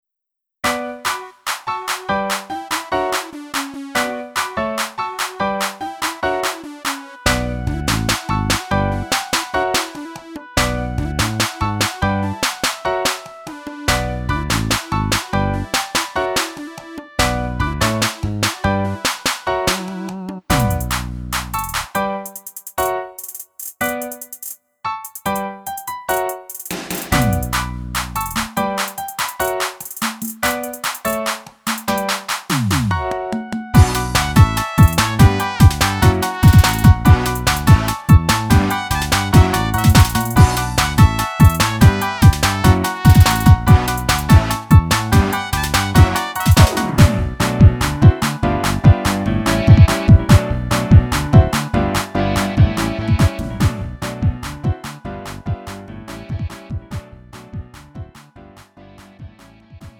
-멜로디MR 가수
음정 원키 장르 가요